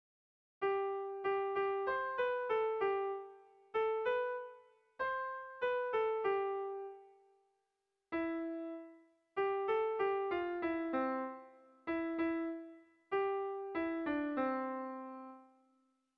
Gabonetakoa
Lauko txikia (hg) / Bi puntuko txikia (ip)
AB